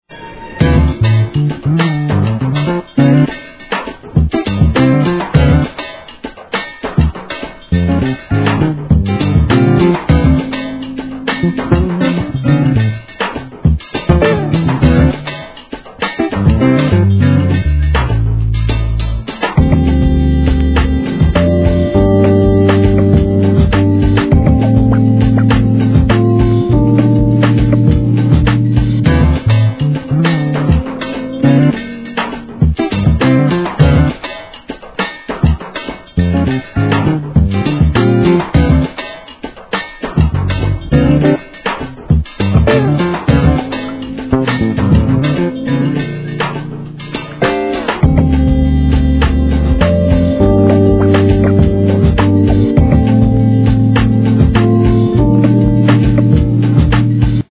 HIP HOP/R&B
程良くジャジーで都会的な品格の際立った極上のインストトラックを全15曲収録した2nd!!